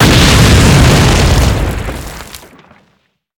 Explosion - Mine sound